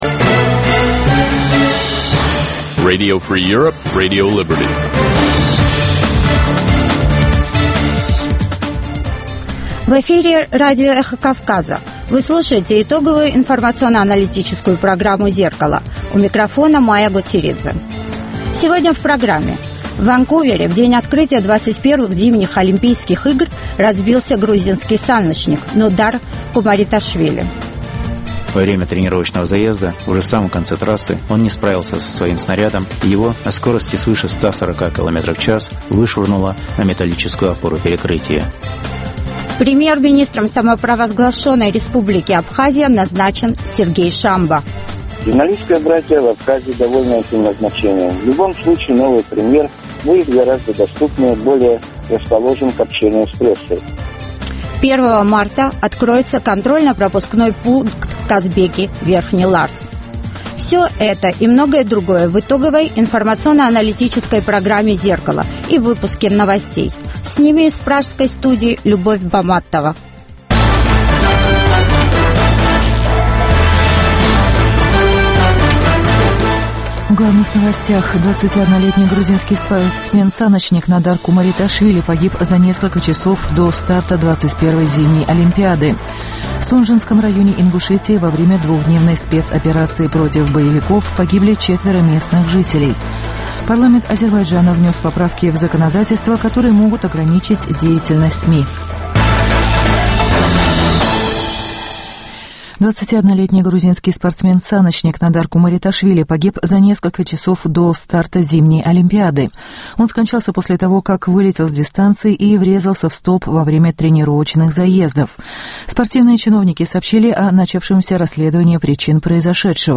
Новости, репортажи с мест, интервью с политиками и экспертами , круглые столы, социальные темы, международная жизнь, обзоры прессы, история и культура.